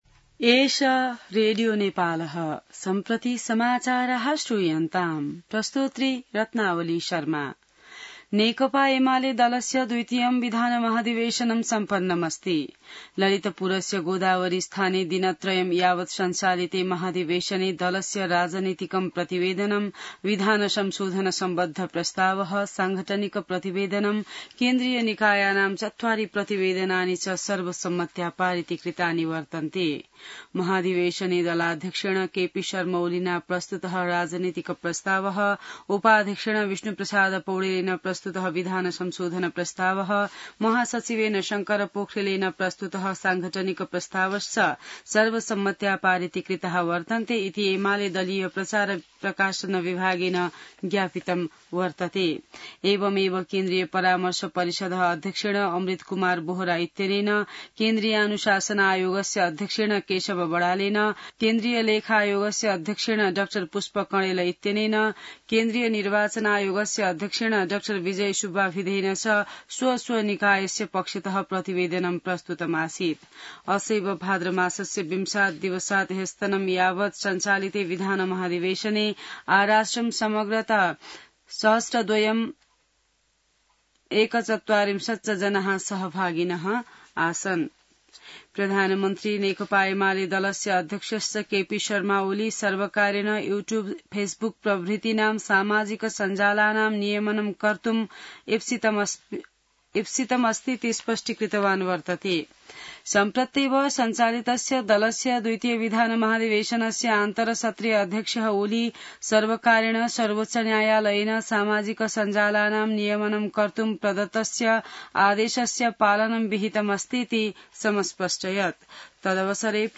An online outlet of Nepal's national radio broadcaster
संस्कृत समाचार : २३ भदौ , २०८२